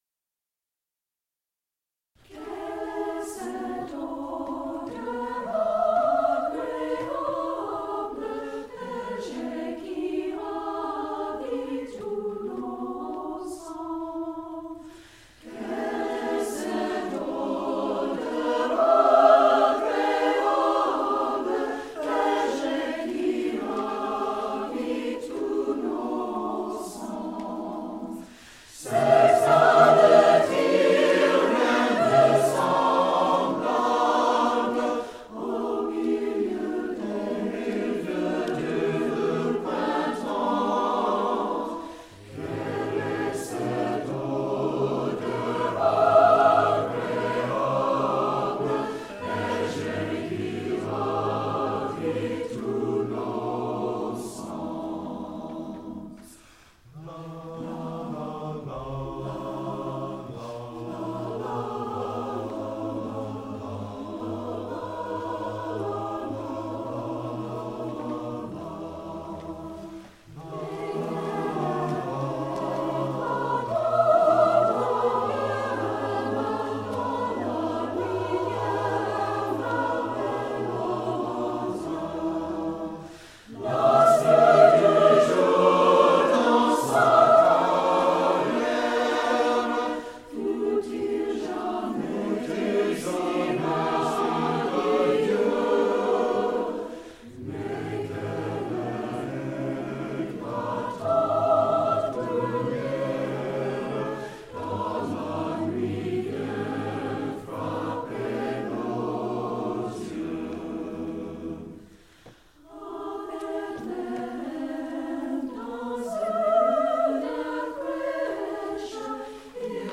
for SATB Chorus a cappella